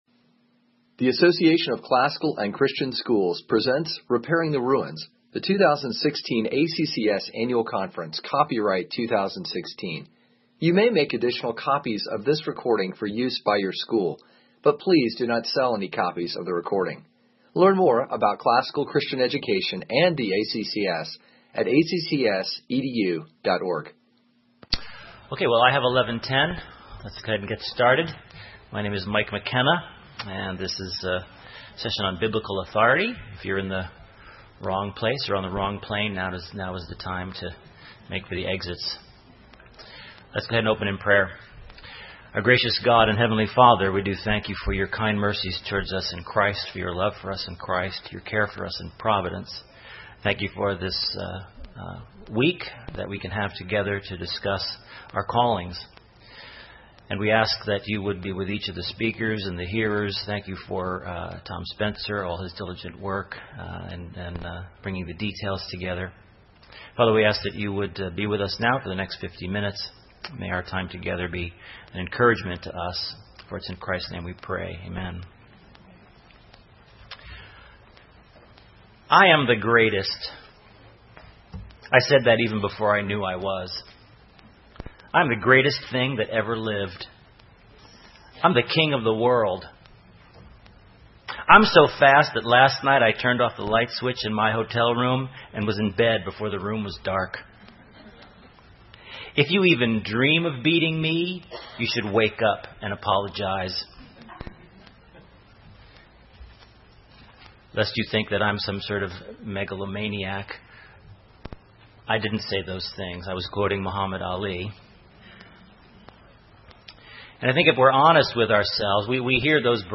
2016 Workshop Talk | 0:51:26 | All Grade Levels, Bible & Theology
Additional Materials The Association of Classical & Christian Schools presents Repairing the Ruins, the ACCS annual conference, copyright ACCS.